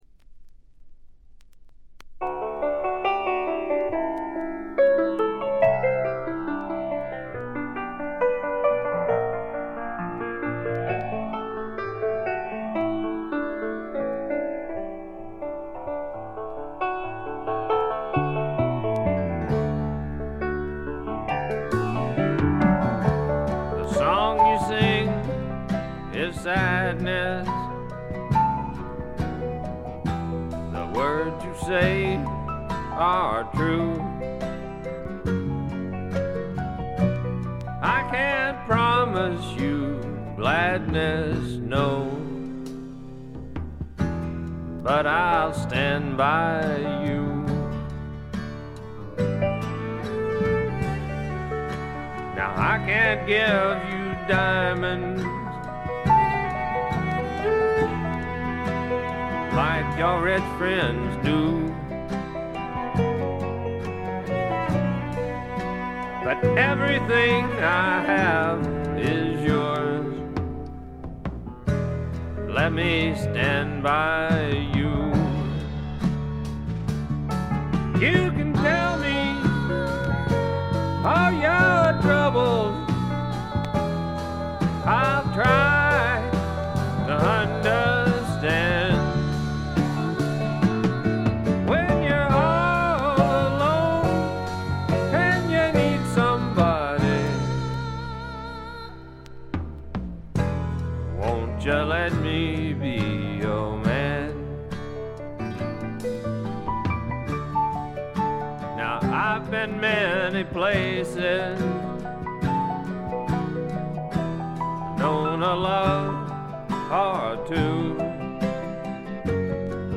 部分試聴ですが、ところどころでチリプチ、散発的なプツ音少し。
いかにもテキサス／ダラス録音らしいカントリー系のシンガー・ソングライター作品快作です。
ヴォーカルはコクがあって味わい深いもので、ハマる人も多いと思いますね。
試聴曲は現品からの取り込み音源です。
Recorded At - January Sound Studio